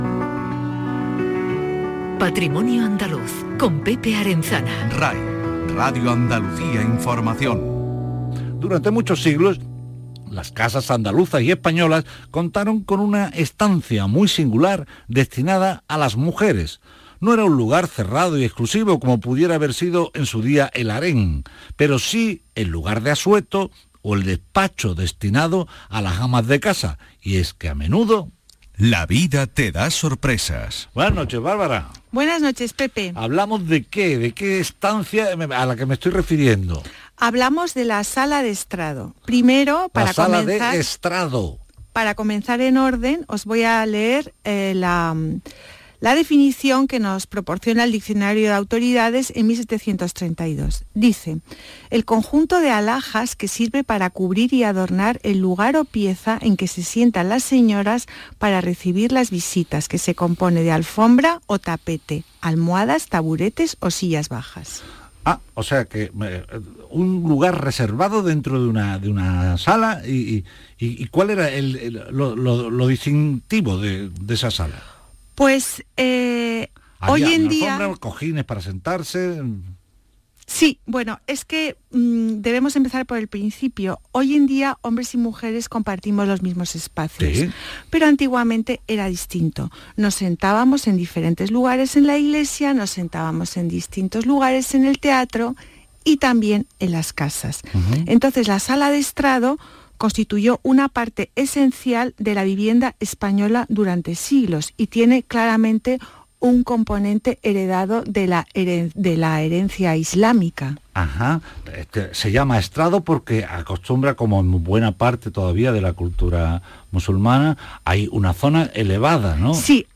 Aquí os dejo mi intervención en el programa de Radio Andalucía Información, «Patrimonio andaluz» del día 16/01/2022.